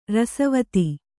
♪ rasavati